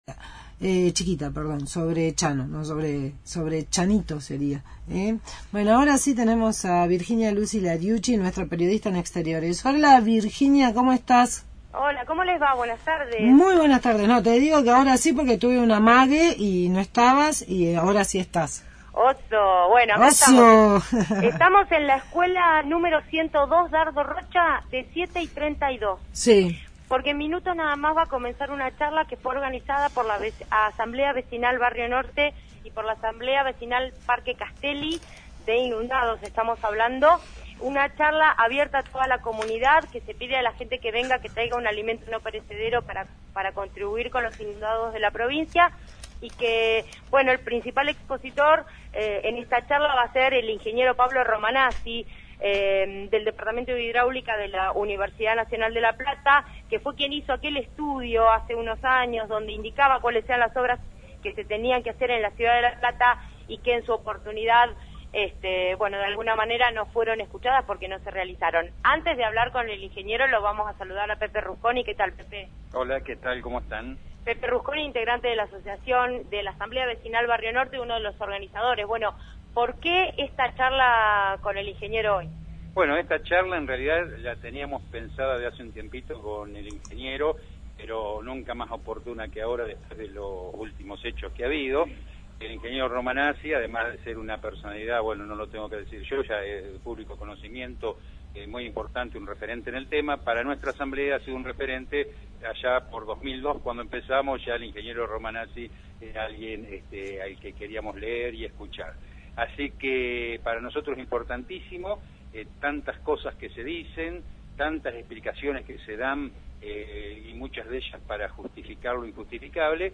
charla debate